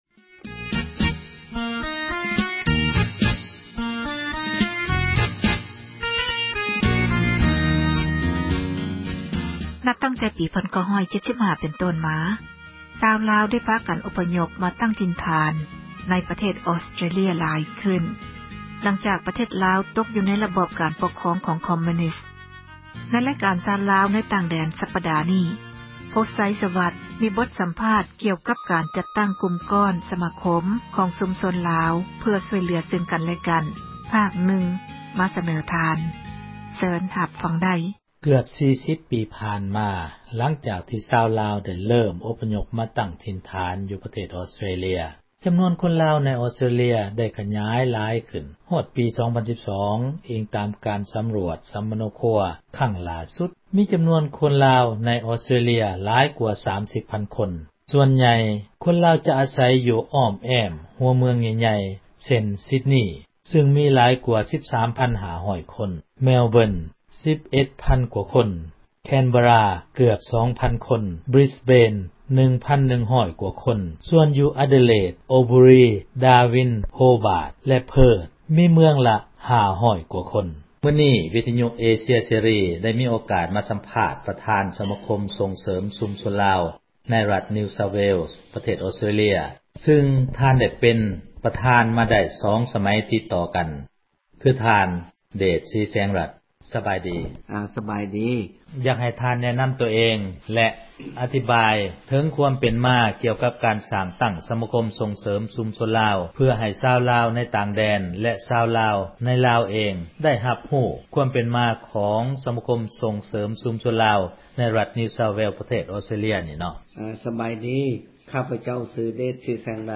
ມີບົດສັມພາດ